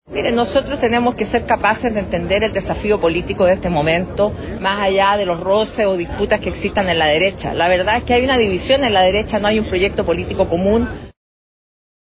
Así, por ejemplo, lo señaló la abanderada del Partido Socialista, Paulina Vodanovic, quien señaló que esta situación da cuenta de que la derecha no tiene un proyecto político común.